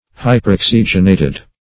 hyperoxygenated - definition of hyperoxygenated - synonyms, pronunciation, spelling from Free Dictionary
Search Result for " hyperoxygenated" : The Collaborative International Dictionary of English v.0.48: Hyperoxygenated \Hy`per*ox"y*gen*a`ted\, Hyperoxygenized \Hy`per*ox"y*gen*ized\, a. (Chem.)